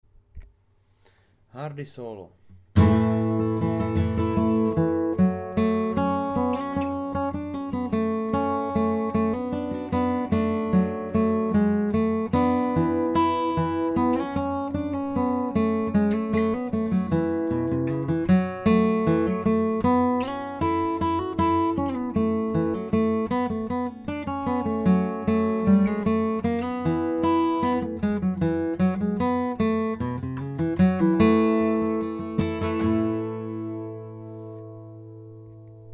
v G Dur 💡Obsáhlá videolekce k písni Hardy